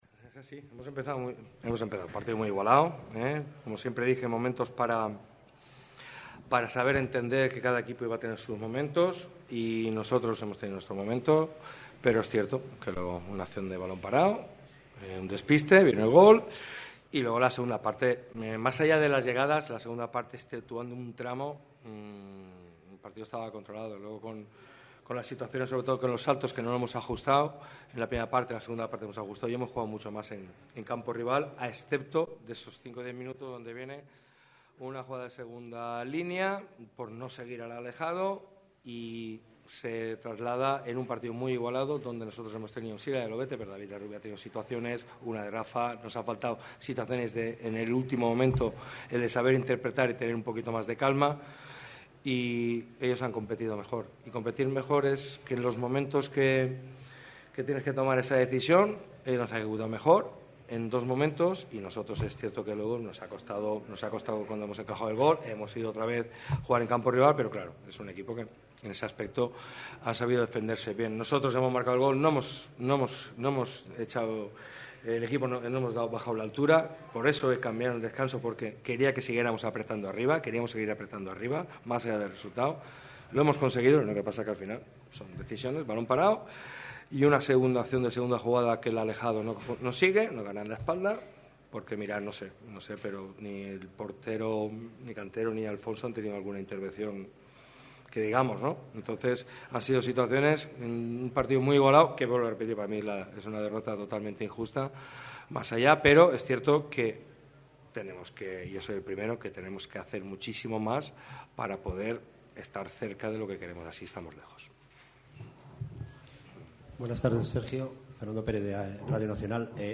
El técnico valora todo esto en la sala de prensa de El Plantío.